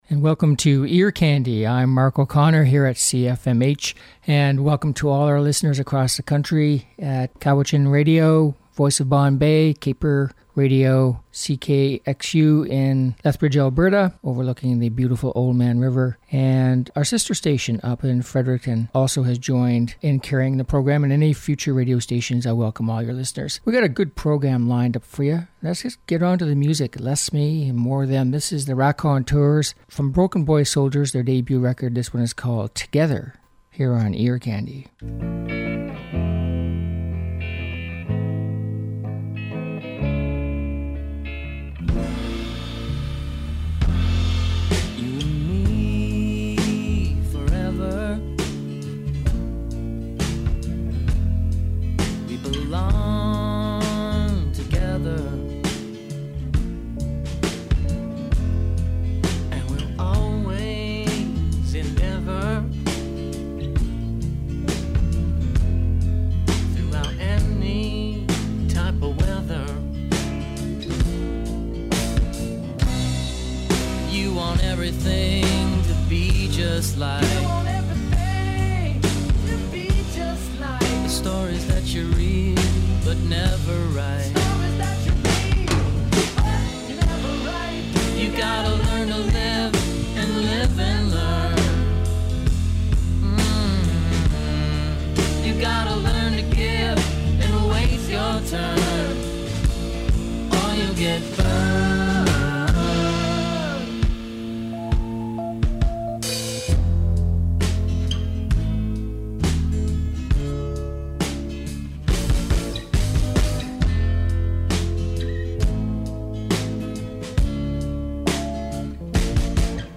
Uptempo Pop and Rock Songs